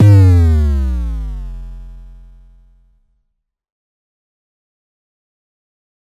ゲームで使用できそうな効果音。
キャラクターが死んだときやミスをした時の効果音。